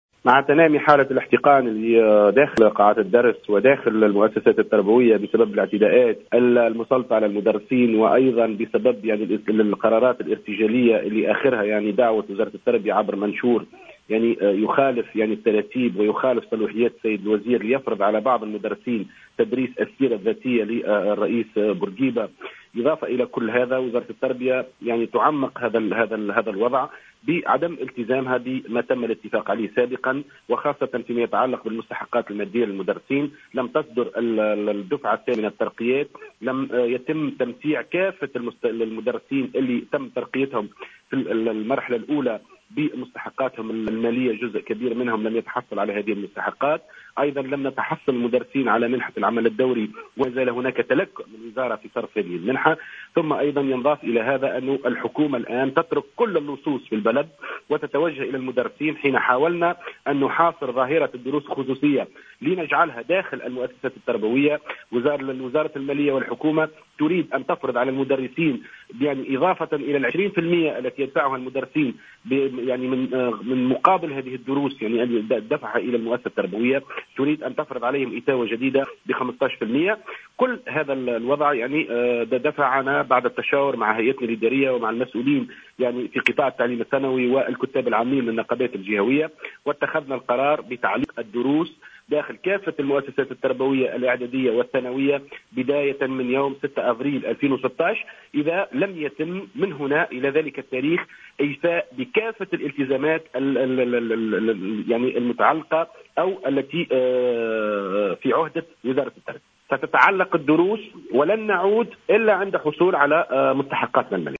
sur les ondes de Jawhara Fm